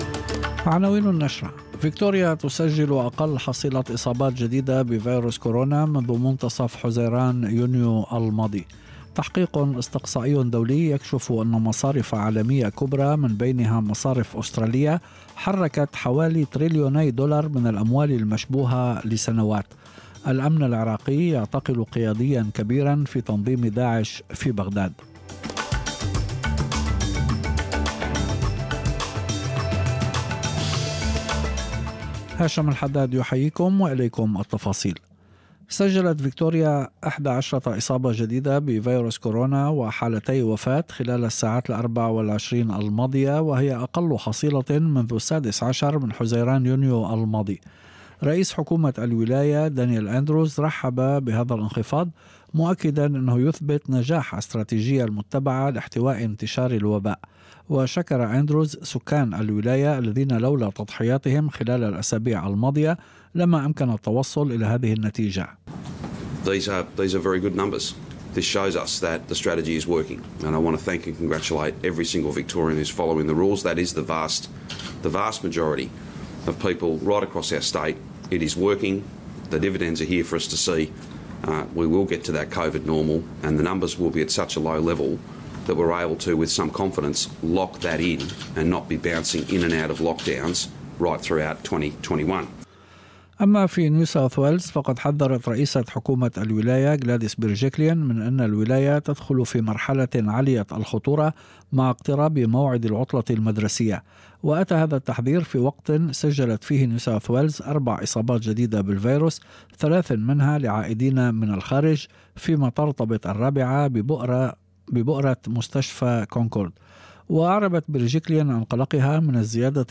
نشرة أخبار المساء 21/9/2020